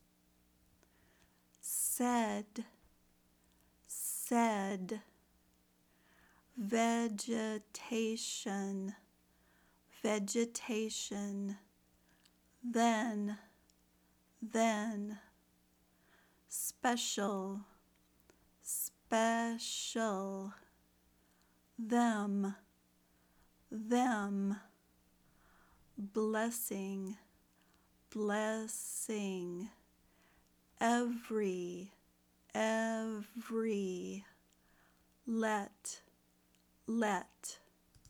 Practice Short “E”
short-e.mp3